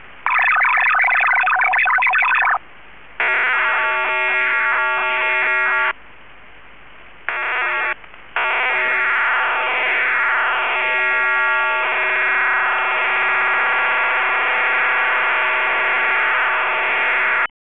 The Medium Speed Modem (MSM) uses 10 parallel data channels in the audio baseband of the transceiver. Each of the data channels is modulated at 125 bps, providing a total capacity of 1250 bps.